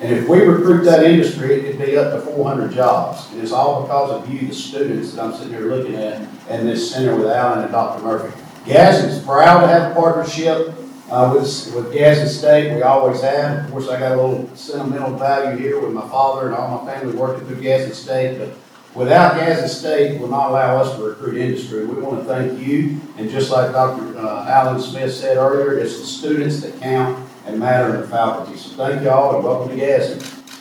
Mayor Craig Ford spoke briefly to the large crowd attending the ribbon cutting ceremony Wednesday at the Gene Haas Advanced Manufacturing and Work Force Skills Center in Gadsden.